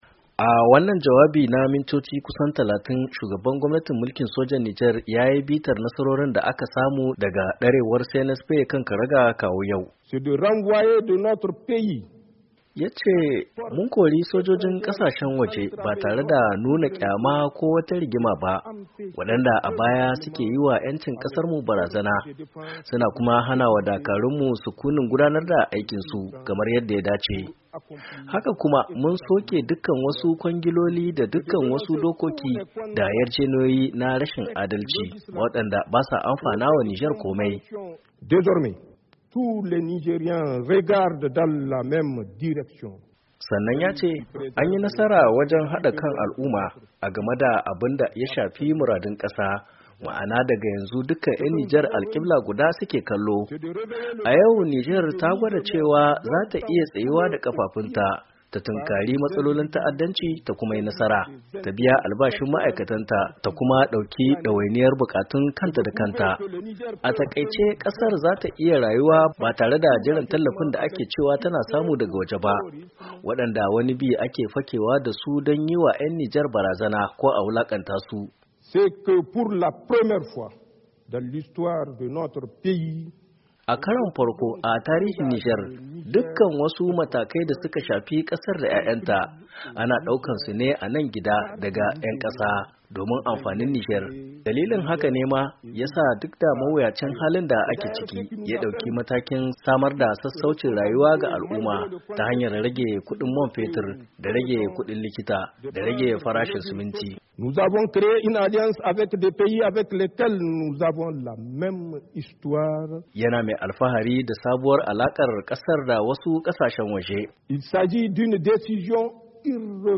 Shugaban gwamnatin mulkin sojan Jamhuryar Nijar Janar Abdourahamane Tiani ya yi jawabi ga al’umma ranar bukin samun 'yancin kai inda ya tabo mahimman batutuwan da suka shafi tafiyar kasar daga lokacin da ya kwaci madafun iko zuwa yau.
JAWABIN JANAR TIANI RANAR JAMHURIYA.